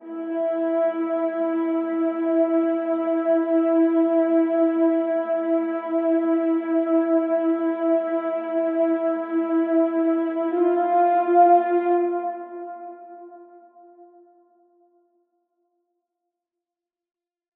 AV_Moonlight_Strings_160bpm_Emin.wav